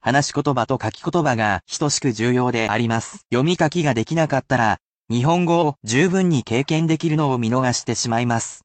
They are spoken at regular speed, so there is no need to repeat after the sentences, unless you are more advanced.